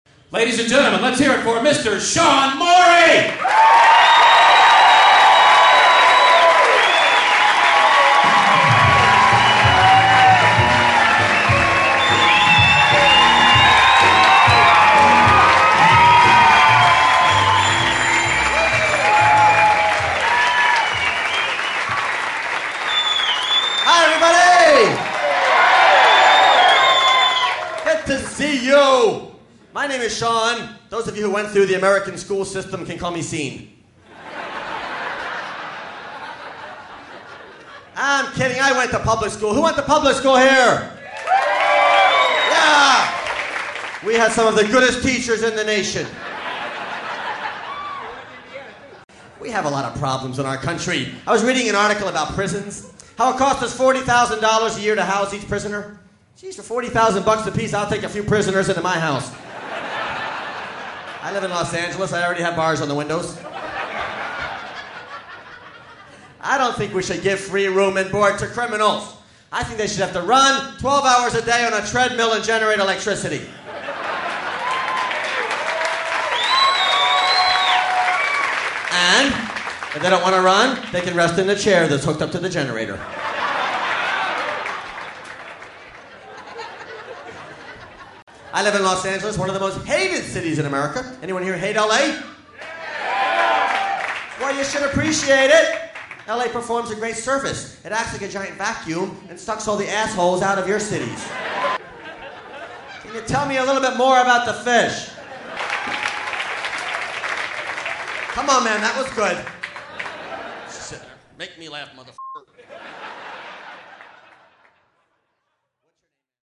--comedy music and standup